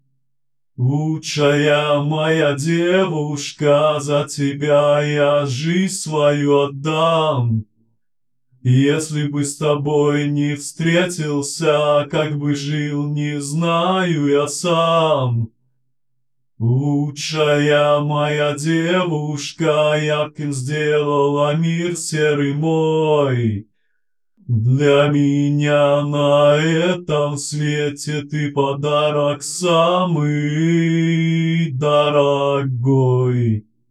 RVC модель сделанная легально, приглашенным певцом. Вот его голос и RVC модель с него.